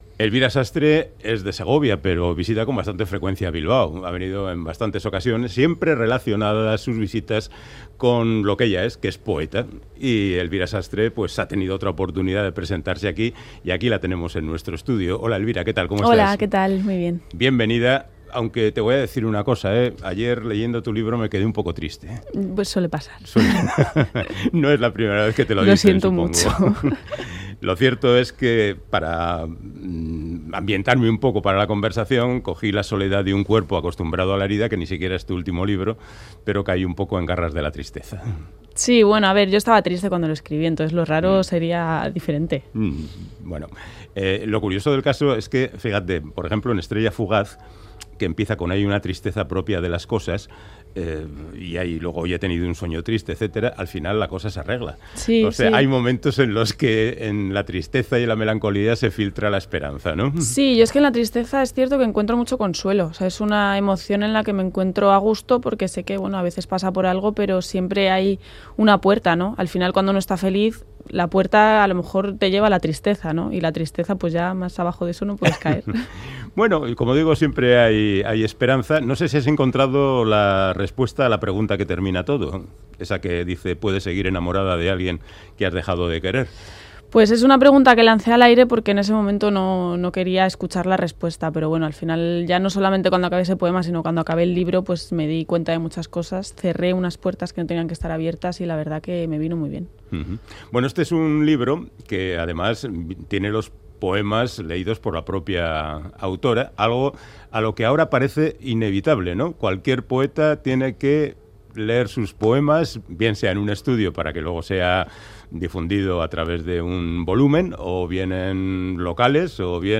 Audio: Charlamos con la escritora y traductora Elvira Sastre de sus últimos poemarios, del impacto de la poesía en los tiempos de las redes sociales, de su trabajo como traductora y de su primera novela